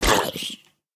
Minecraft Version Minecraft Version 25w18a Latest Release | Latest Snapshot 25w18a / assets / minecraft / sounds / mob / zombie_villager / hurt1.ogg Compare With Compare With Latest Release | Latest Snapshot
hurt1.ogg